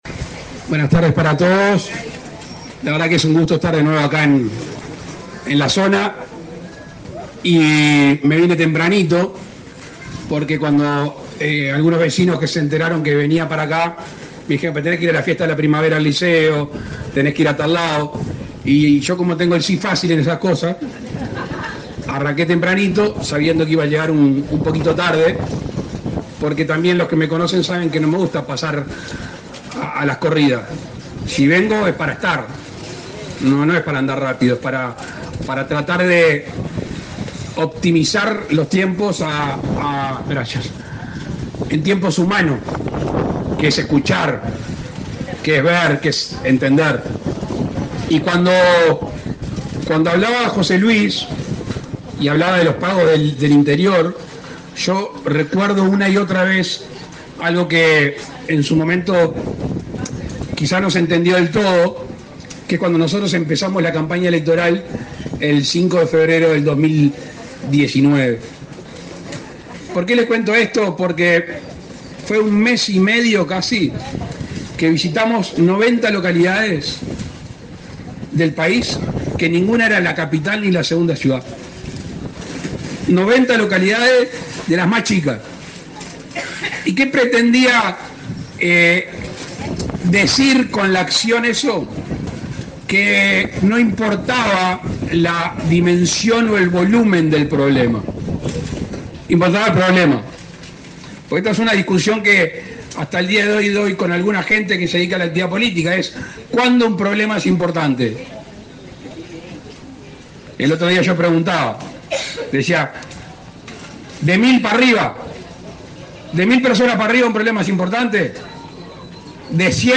Palabras del presidente de la República, Luis Lacalle Pou, en Lascano
Palabras del presidente de la República, Luis Lacalle Pou, en Lascano 08/11/2024 Compartir Facebook X Copiar enlace WhatsApp LinkedIn El presidente de la República, Luis Lacalle Pou, participó, este 7 de noviembre, en la inauguración de obras en la ruta n.°15, en la localidad de Lascano, Rocha.